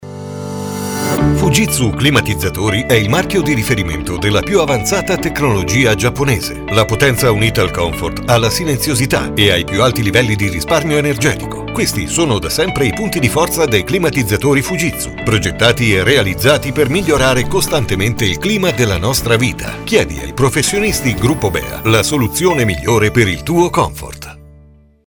FujitsuSpot.mp3